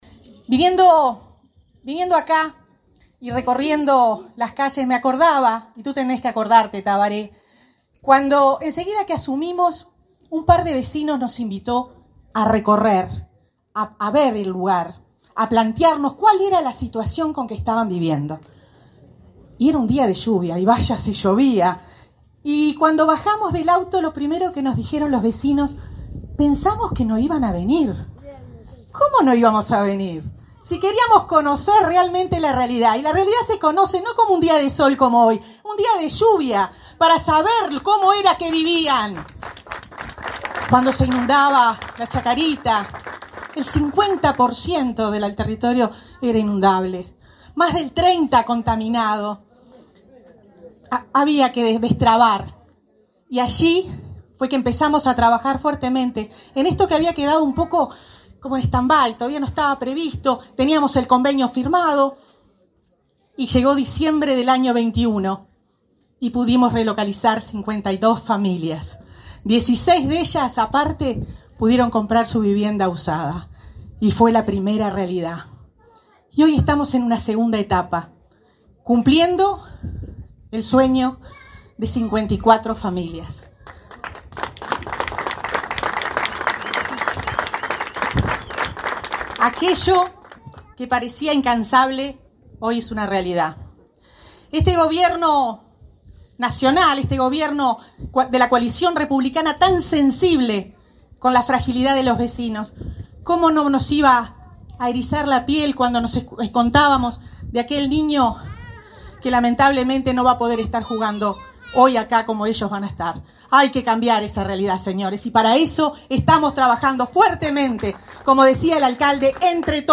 Palabras de la ministra de Vivienda, Irene Moreira